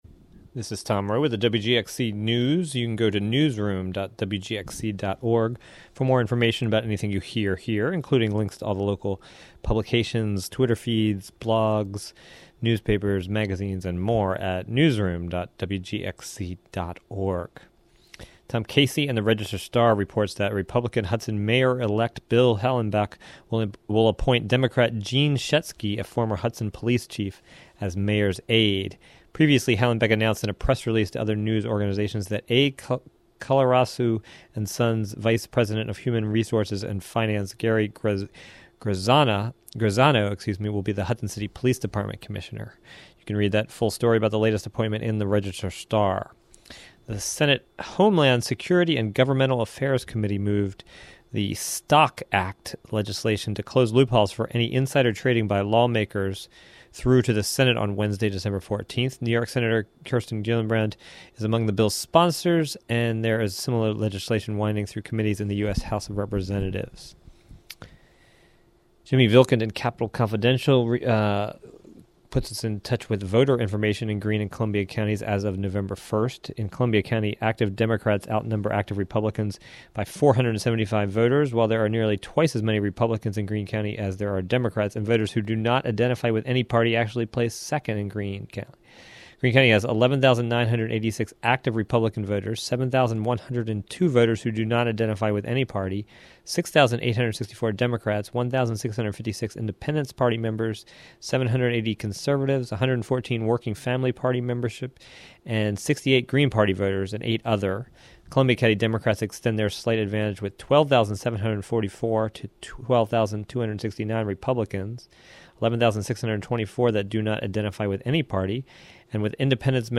With interview